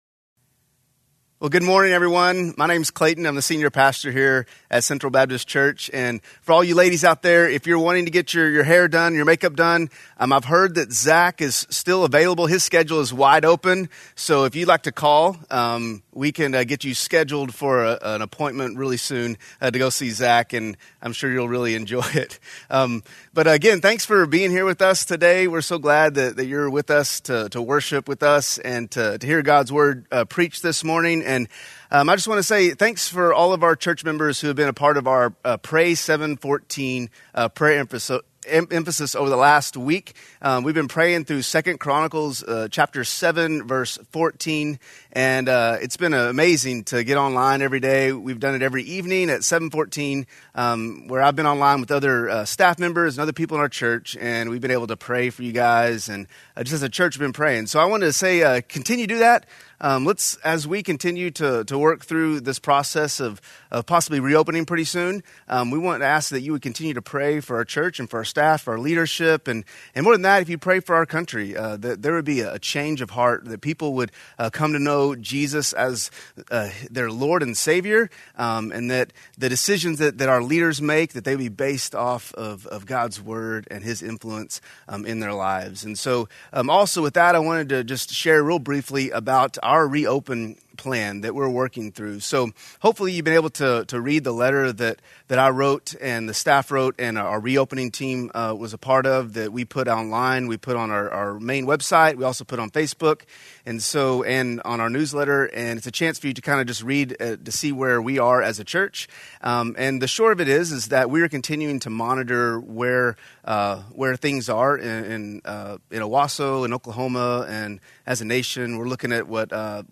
A message from the series "Asking for a Friend."
Messages from Mother's Day at CBC.